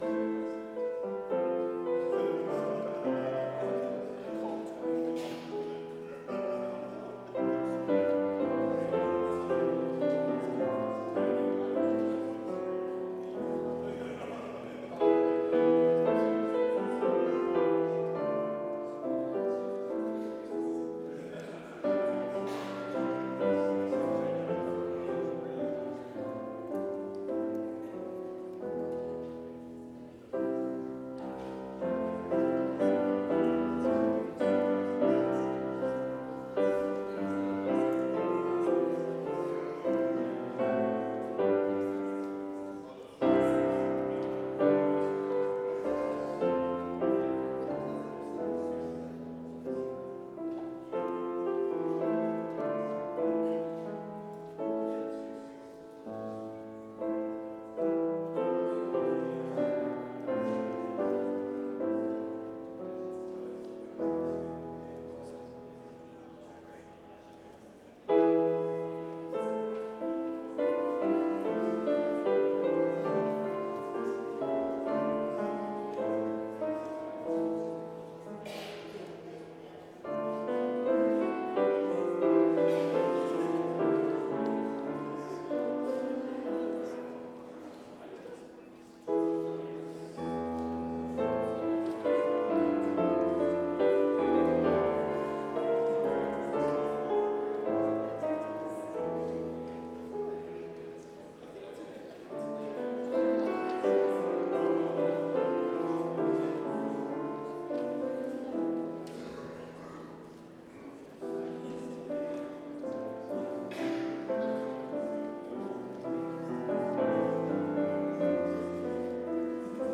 Complete service audio for Chapel - Thursday, January 30, 2025
Watch Listen Complete Service Audio file: Complete Service Sermon Only Audio file: Sermon Only Order of Service Psalm 63 - O Lord, I Will Sing of Your Constant Love - C. Walker Soloist: O Lord, I will sing… / Cong.: O Lord, I will sing…